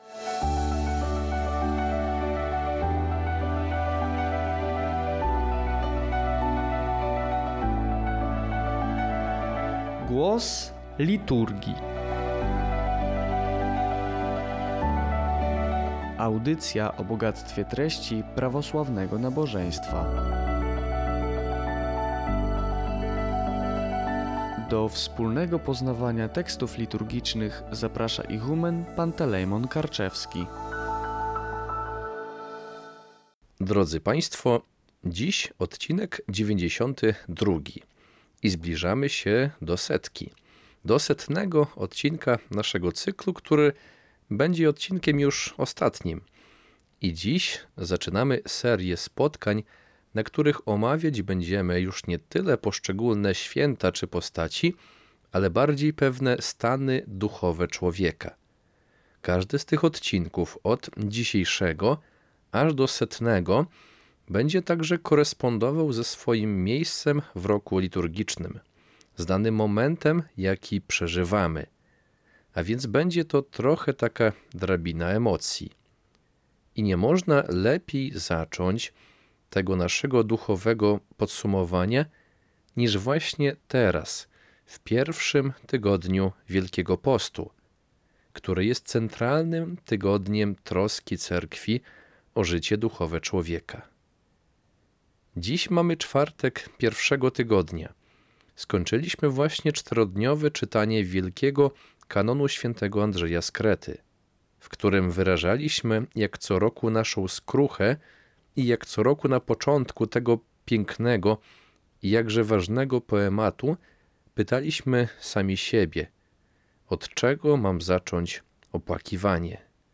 Audycja „Głos Liturgii” co dwa tygodnie.